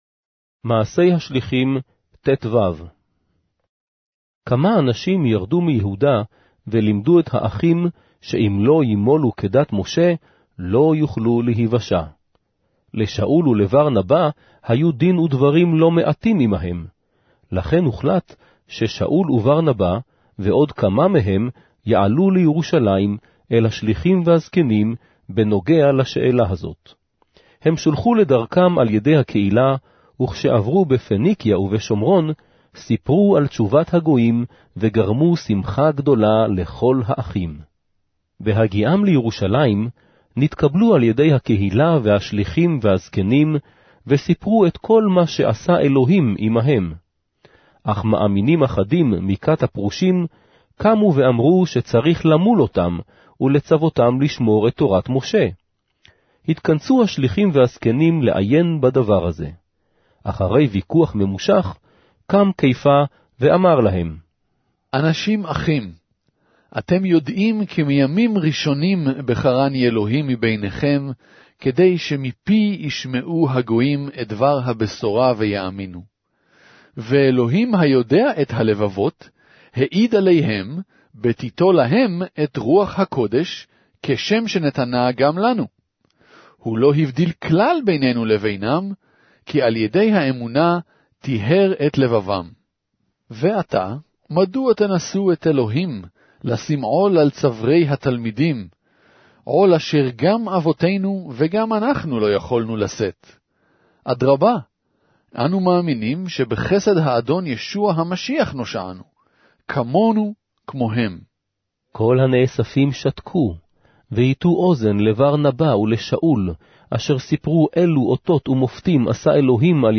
Hebrew Audio Bible - Acts 16 in Nlt bible version